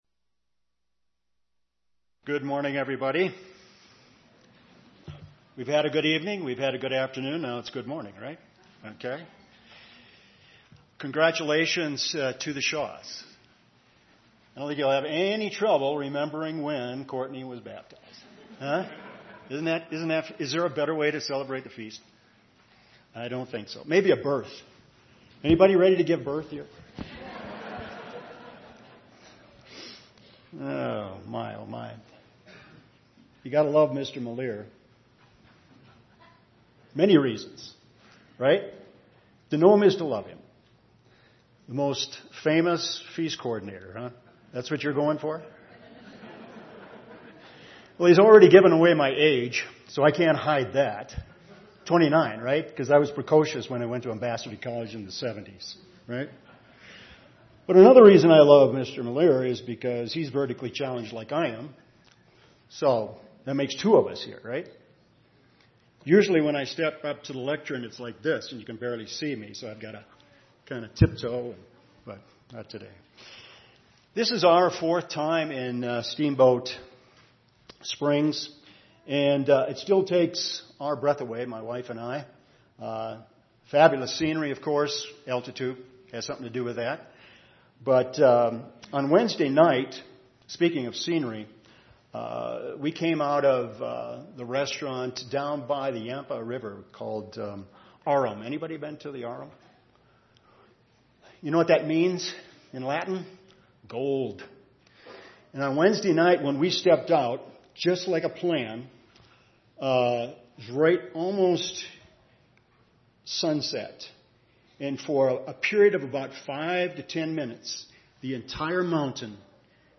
This sermon was given at the Steamboat Springs, Colorado 2014 Feast site.